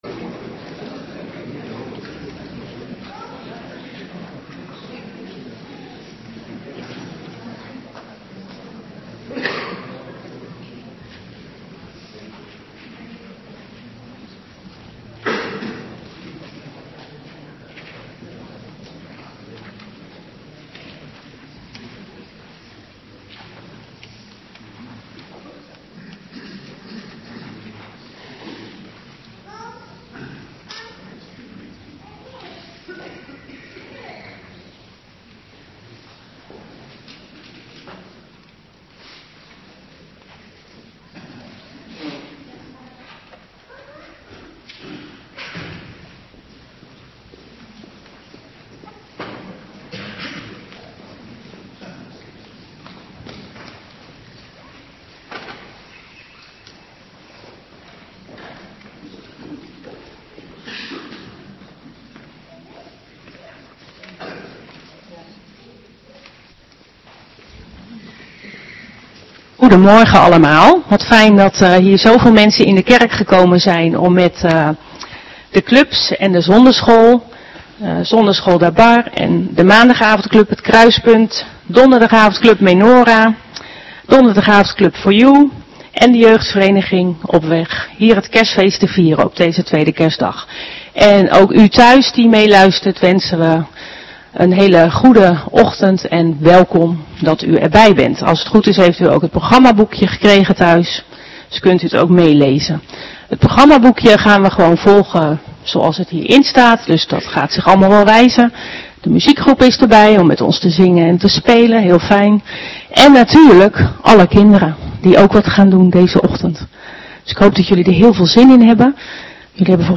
Morgendienst 26 december 2025
Dienst met en voor de jeugd van onze gemeente. Samen Kerstfeest vieren.